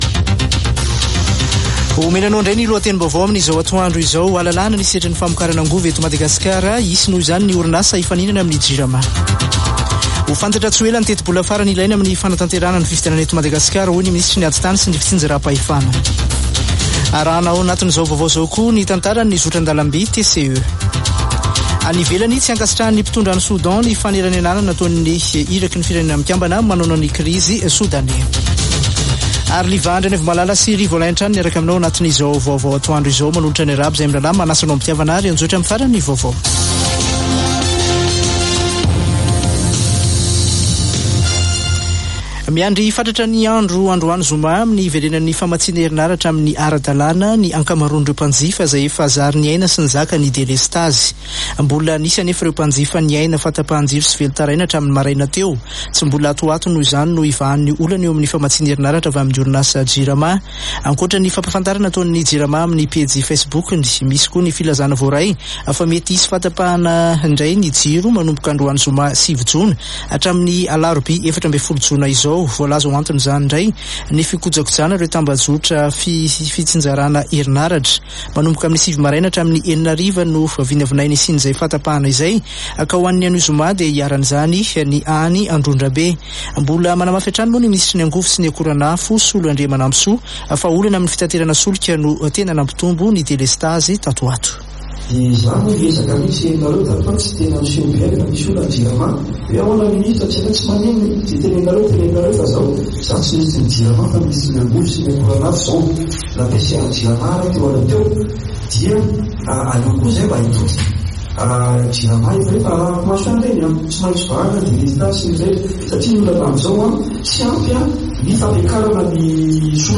[Vaovao antoandro] Zoma 9 jona 2023